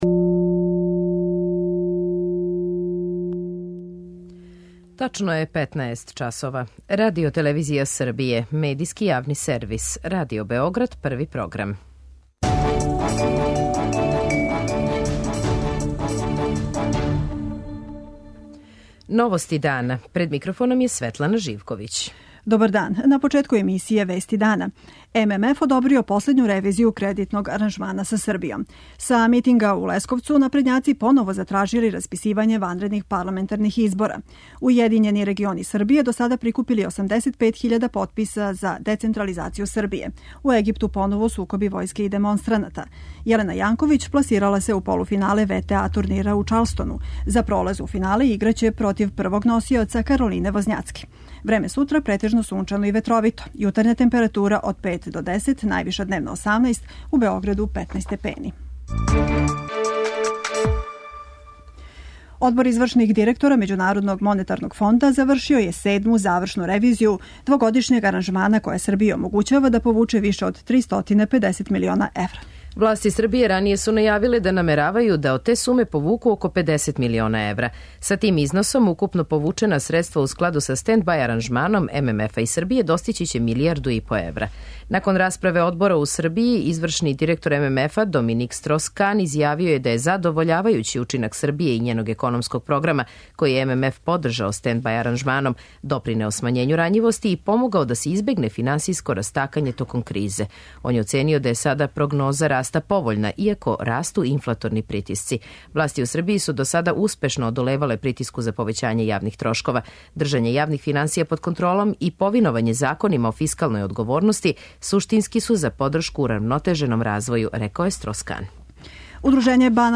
У Новостима чућемо извештај и са митинга опозиције на главном градском тргу у Лесковцу под геслом „Избори се за промене" који је окупио симпатизере Српске напредне странке, Нове Србије, Покрета Снага Србије и Покрета социјалиста.